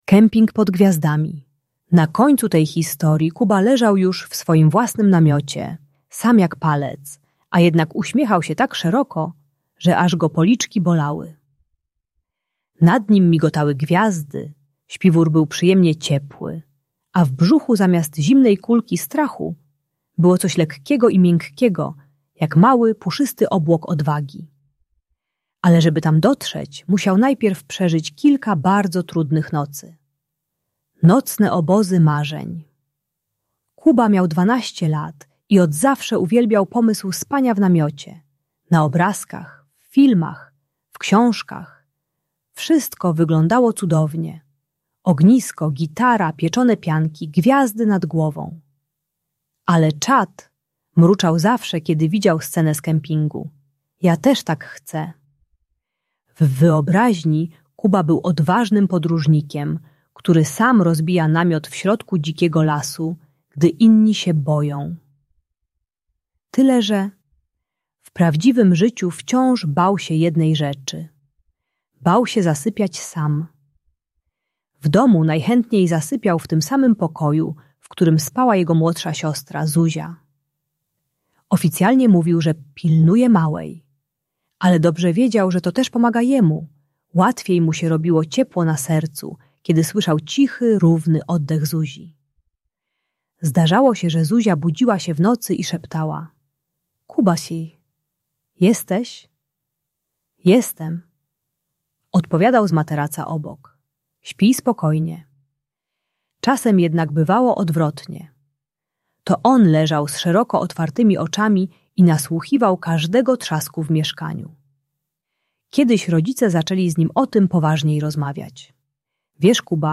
Kemping pod Gwiazdami: story o odwadze - Lęk wycofanie | Audiobajka